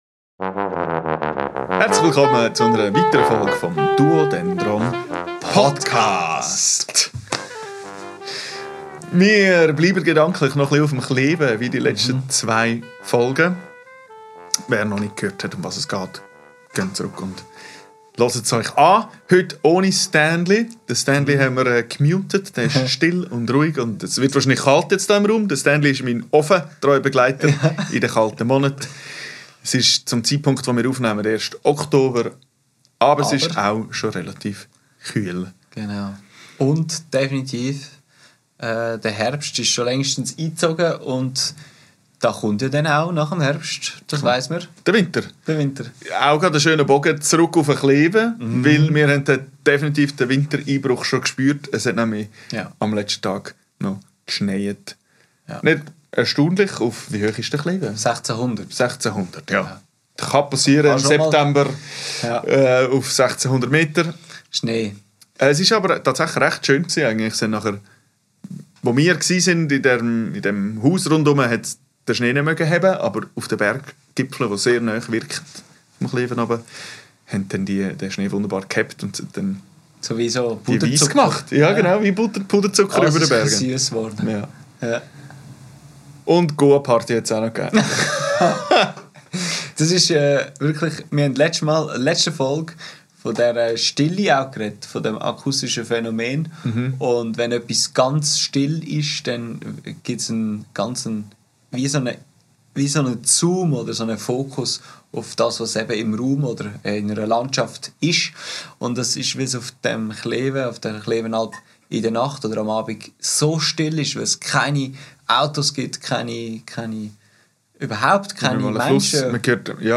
Das dritte Stück aus der Klewen-Session! Auf den Spuren nach neuen Klängen